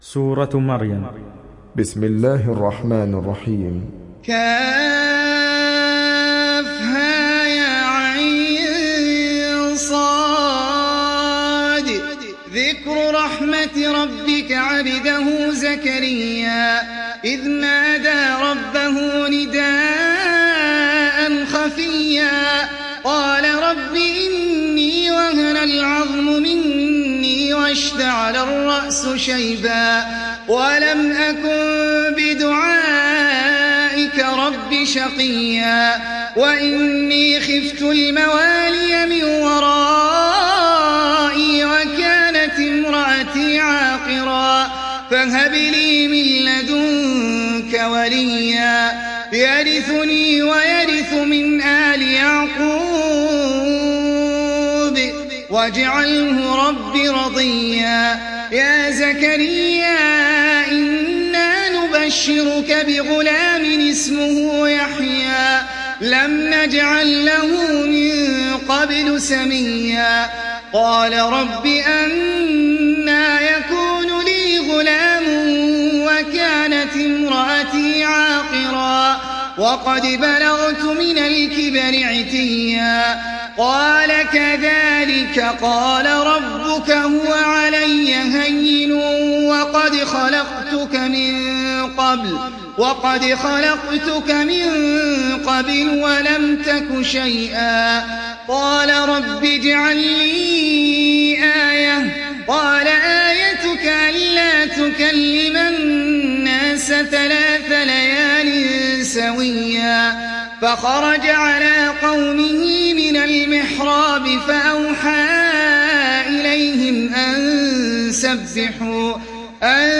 تحميل سورة مريم mp3 بصوت أحمد العجمي برواية حفص عن عاصم, تحميل استماع القرآن الكريم على الجوال mp3 كاملا بروابط مباشرة وسريعة